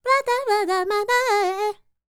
QAWALLI 14.wav